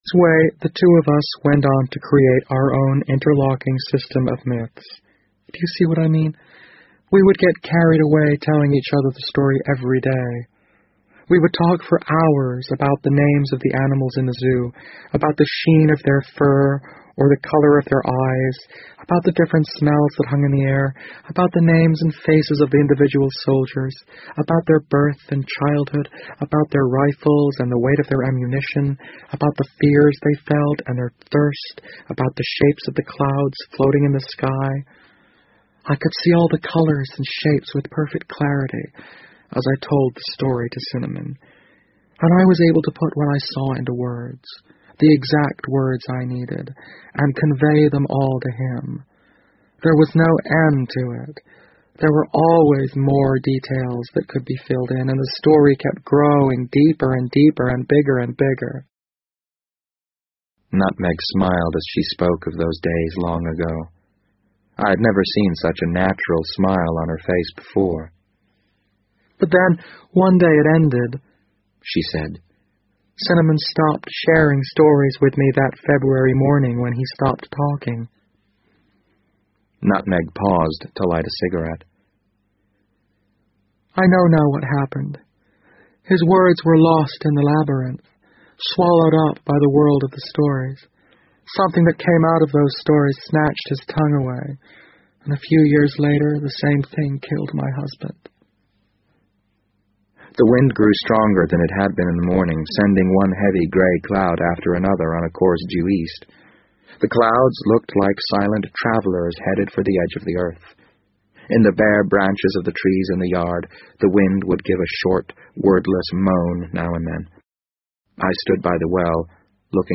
BBC英文广播剧在线听 The Wind Up Bird 011 - 15 听力文件下载—在线英语听力室